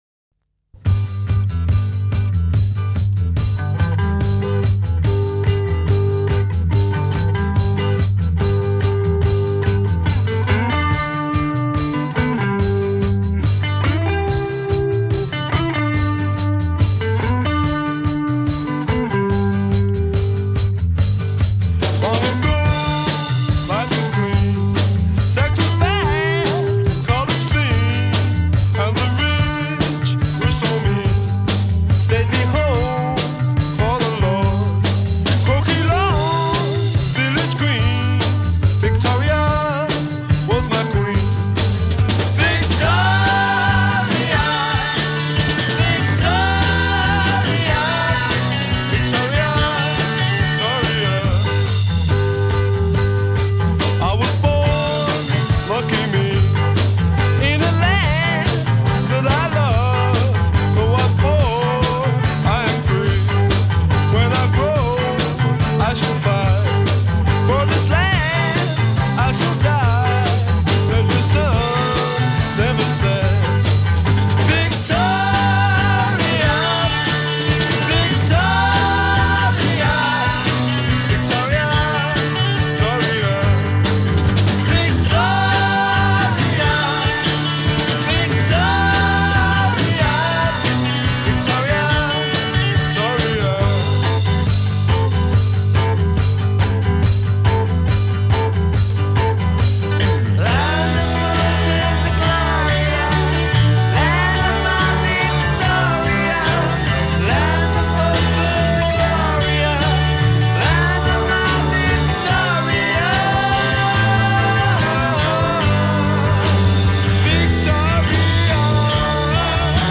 intro     introduce verse melody, double-tracked guitar line
refrain     group harmonies b
chorus     change in underlying rhythm d
verse     guitar solo